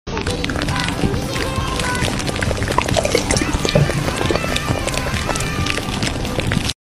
free asmr sound for fanpages sound effects free download